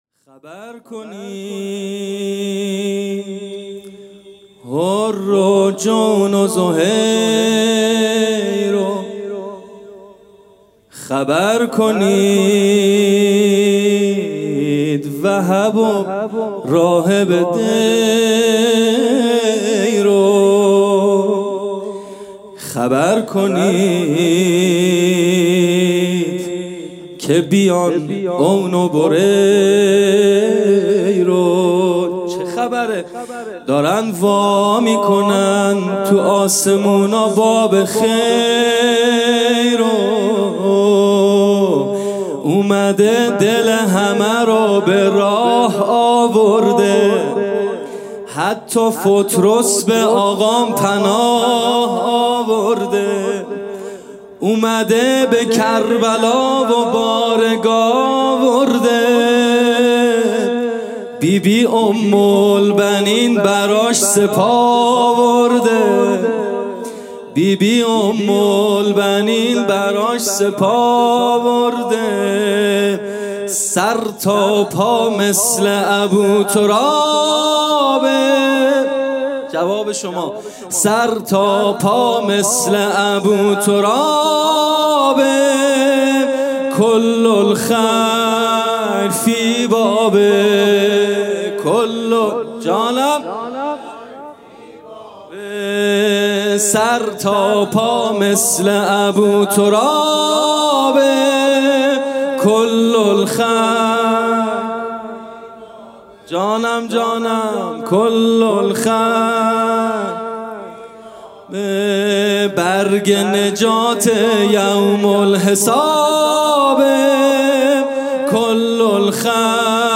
جلسه هفتگی
music-icon سرود